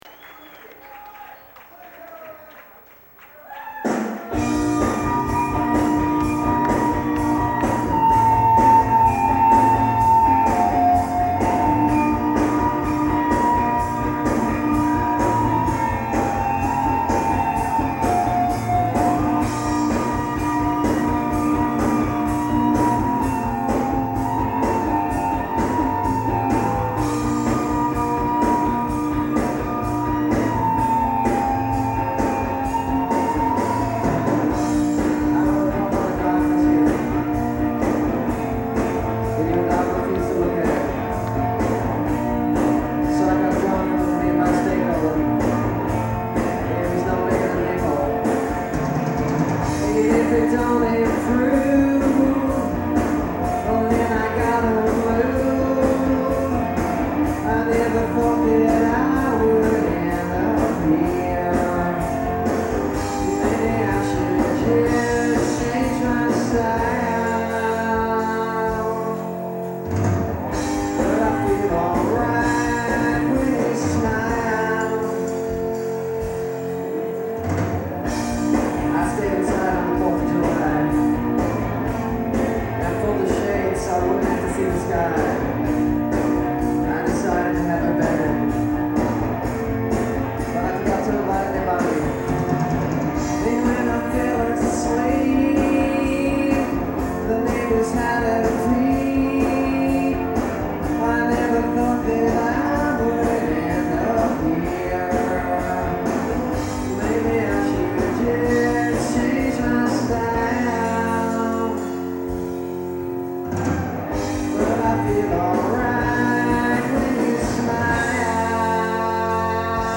live in Philadelphia in 1994
Trocadero, Philadelphia, PA, USA